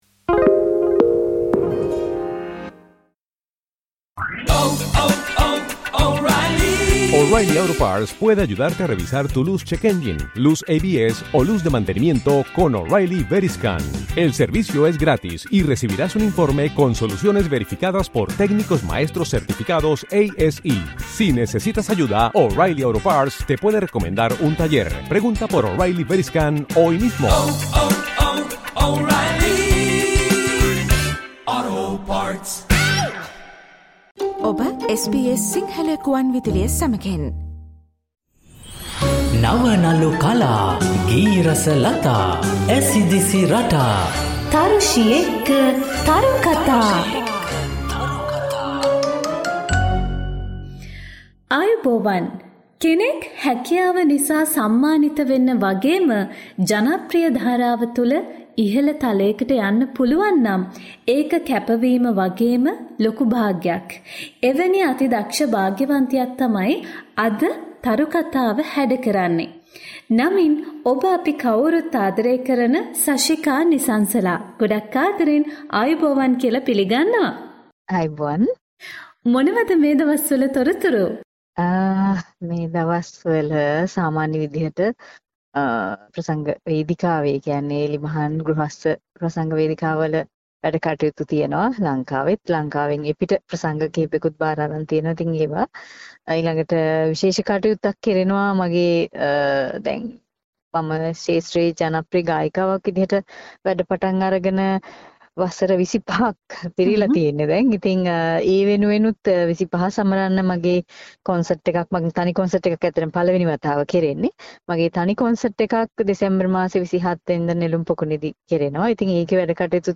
ශ්‍රී ලාංකේය කලා ලෝකයේ රසබර කතාබහක්